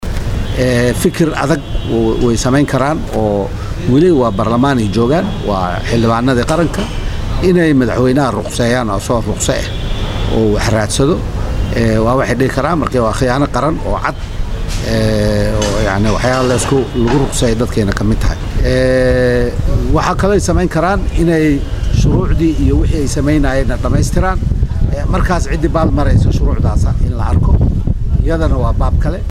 Muqdisho(INO)- Cabdullaahi Goodax Barre oo ka mid ah mudanayaasha baarlamaanka dowladda Soomaaliya oo la hadlay warbaahinta ayaa qiyaano qaran ku tilmaamay go’aankii shalay uu qaatay madexweynaha Soomaaliya mudane Xasan Sheekh Maxamuud.